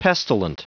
Prononciation du mot pestilent en anglais (fichier audio)
Prononciation du mot : pestilent